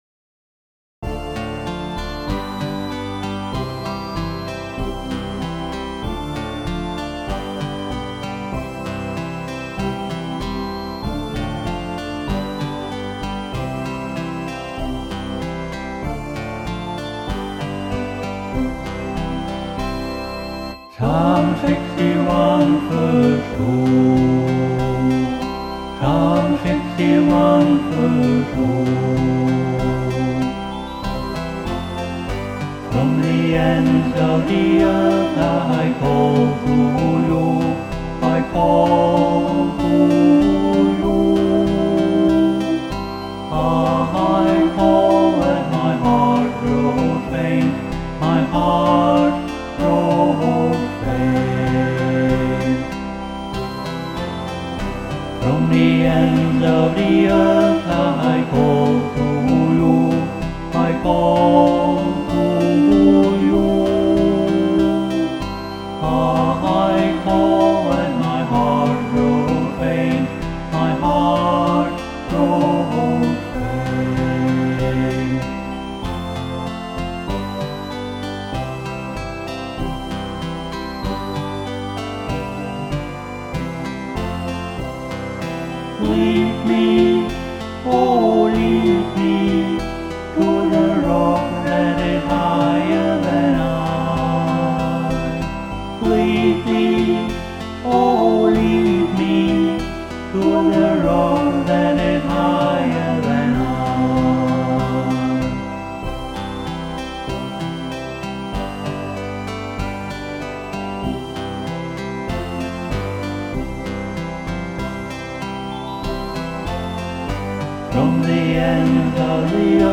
[Karaoke Video with vocal]